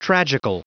Prononciation du mot tragical en anglais (fichier audio)
Prononciation du mot : tragical